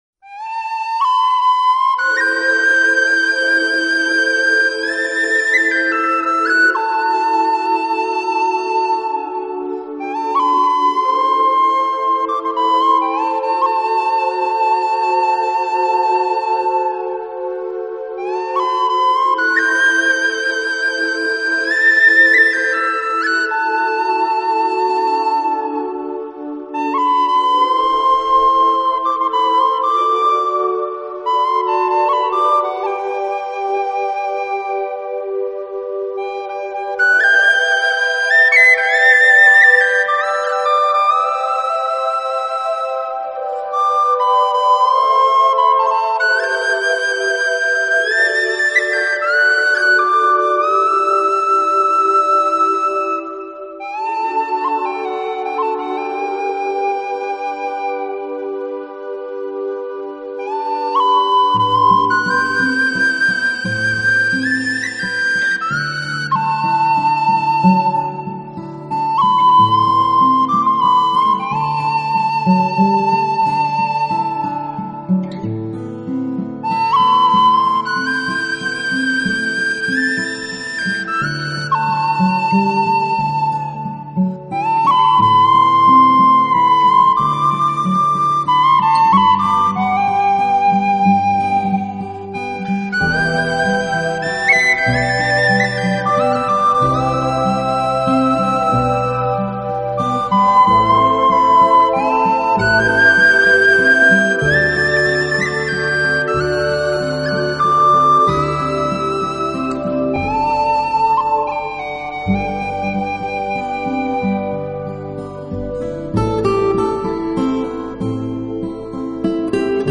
音乐类型: New Age / Celtic